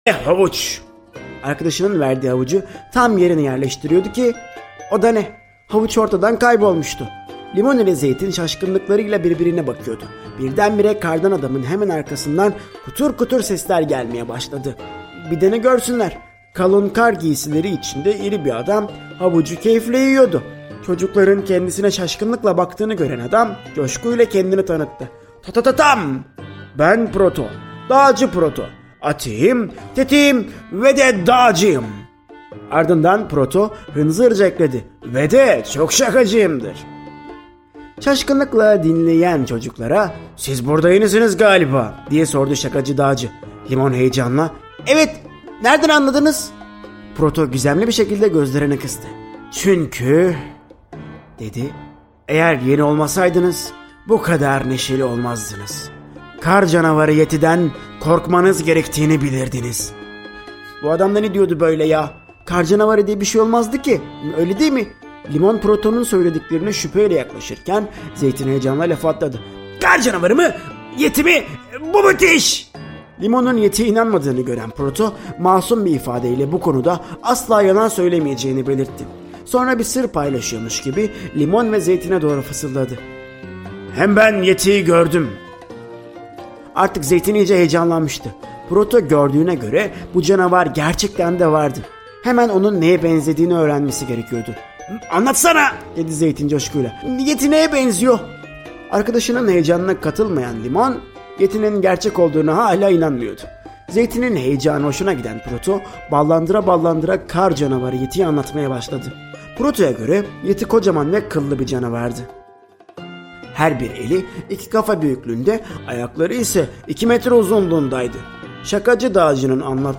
Limon ile Zeytin - Kar Adam - Seslenen Kitap